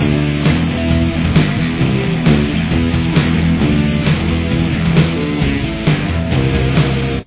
Main Riff